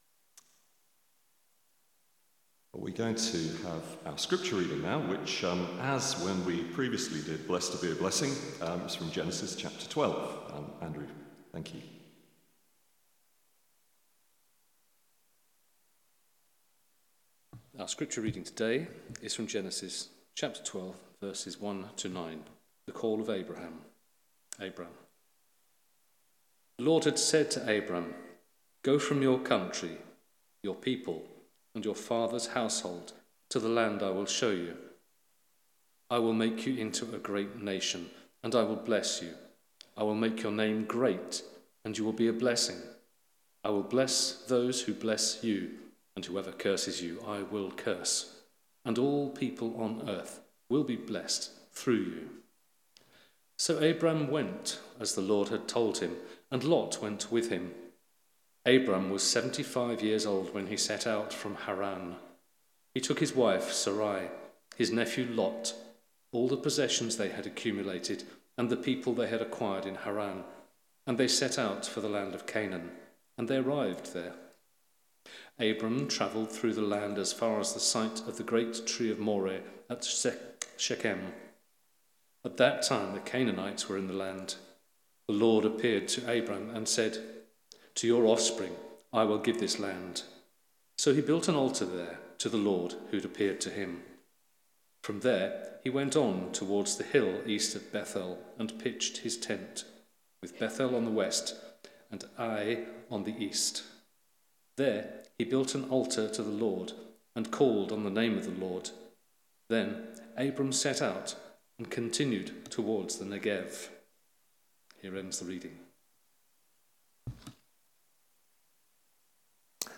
Sermon (Audio) - Well Street United Church